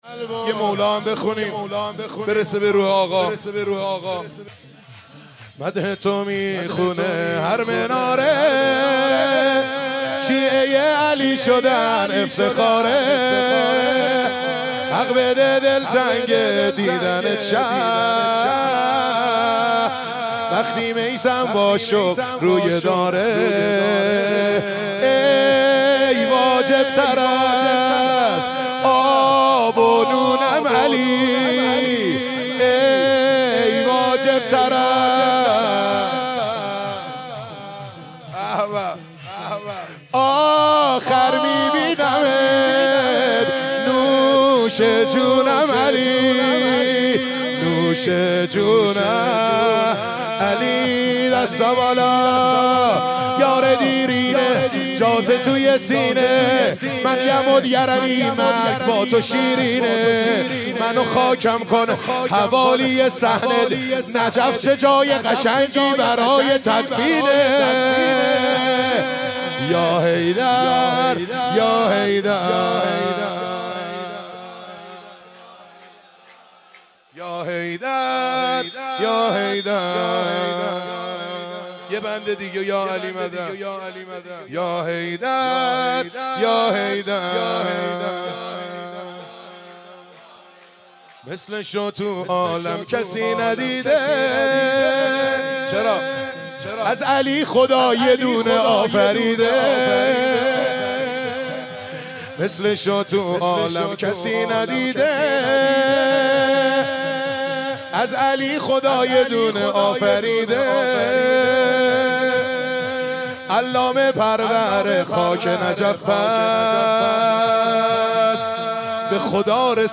شور3